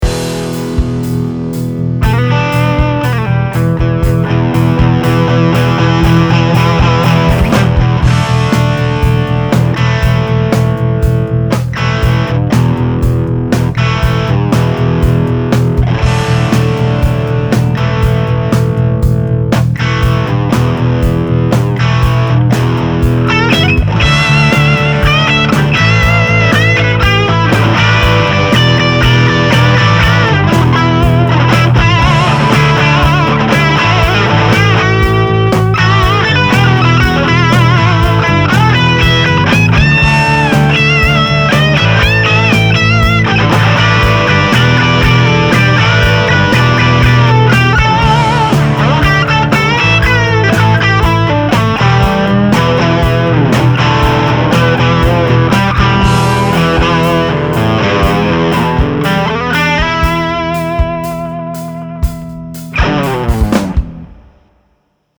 Gitarové Kombo Trubica Zosilnovača
Metropolitan20Angus.mp3